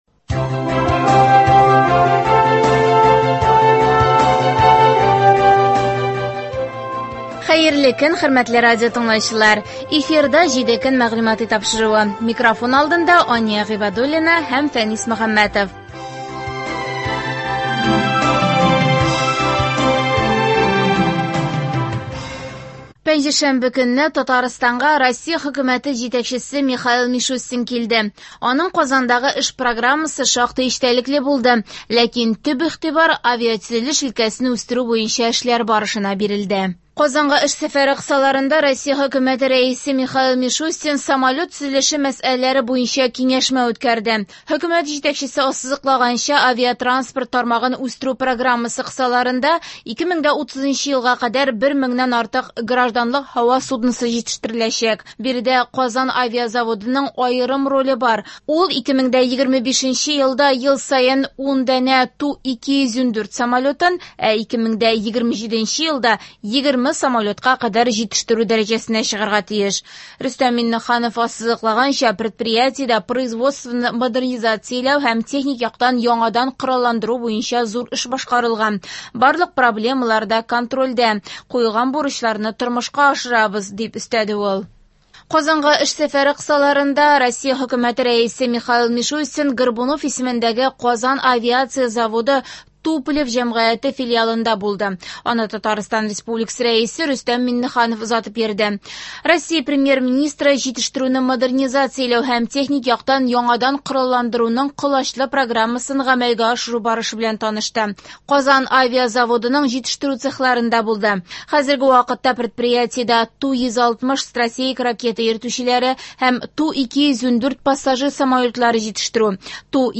Атналык күзәтү.